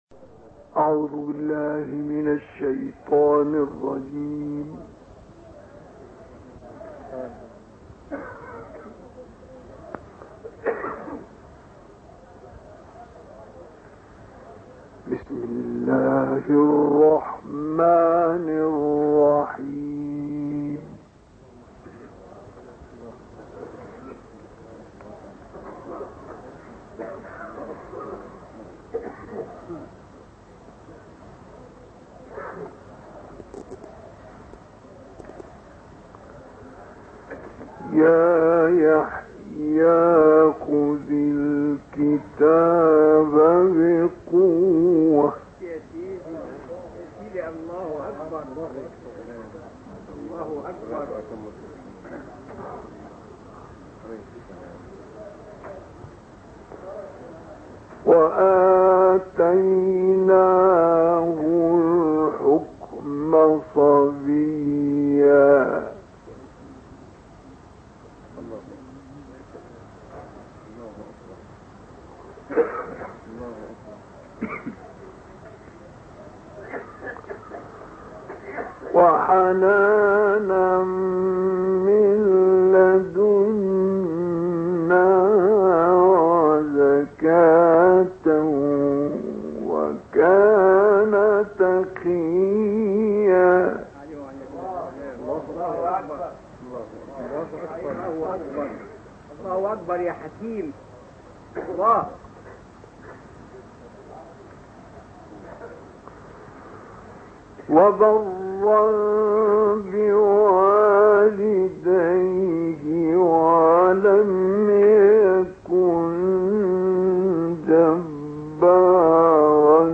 تلاوت اکبرالقرا از سوره مریم(س)